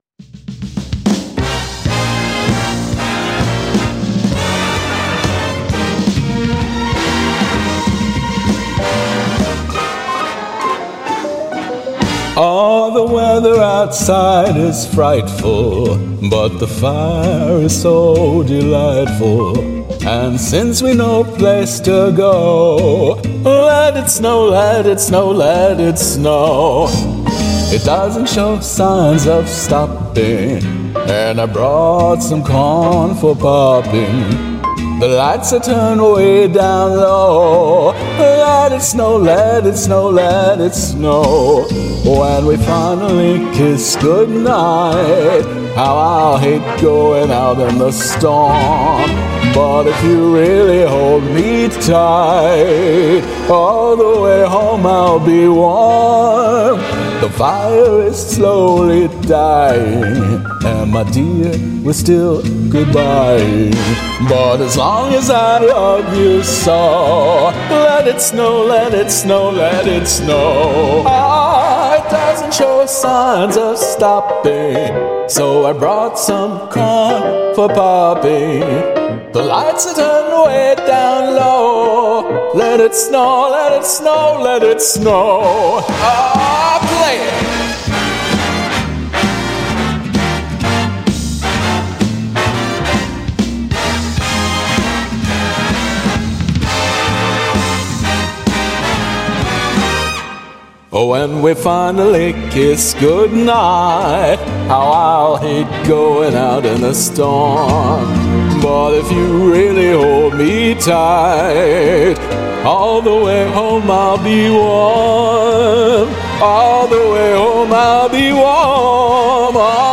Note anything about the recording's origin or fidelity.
for my Princeton class's 2020 virtual holiday party